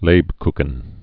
(lābkkən, lāpkən)